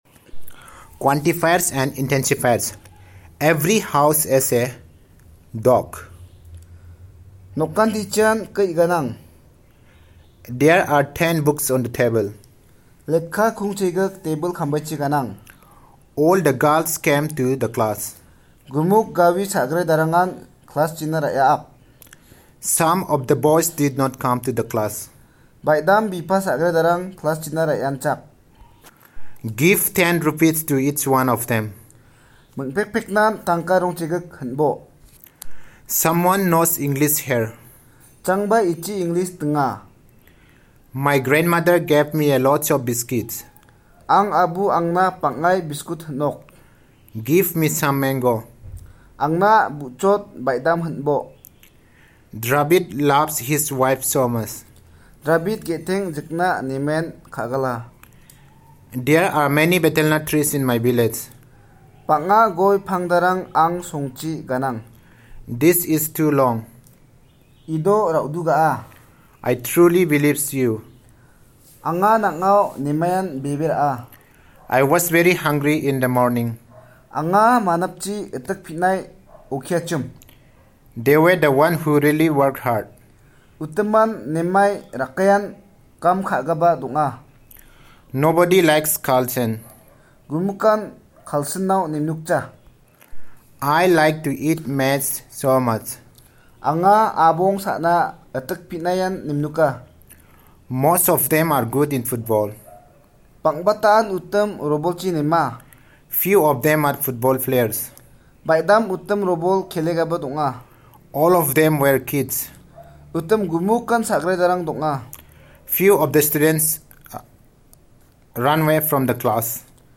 Elicitation of sentences about quantifiers and intensifers